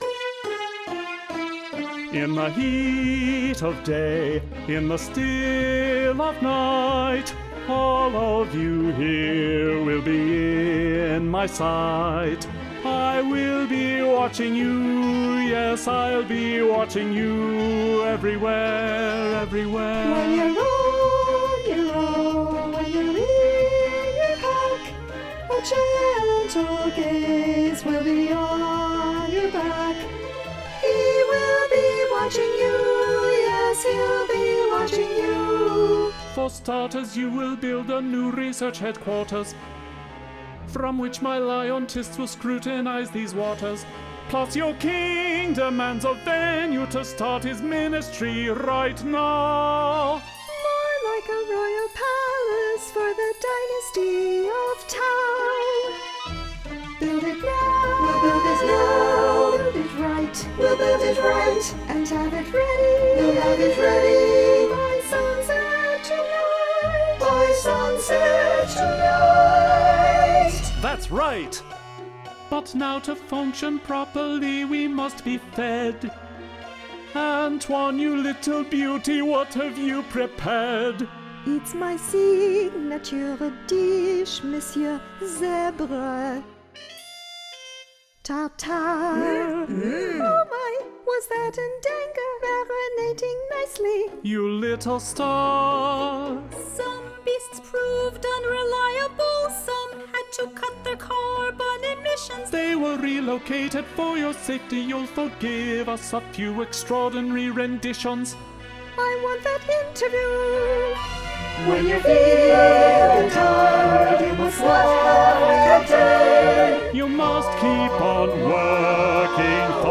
Inspired by the music of South Africa, Rain Dance is a 75-minute, humorous, entirely-sung political drama inspired by Tish Farrell's story, "The Hare Who Would Not Be King".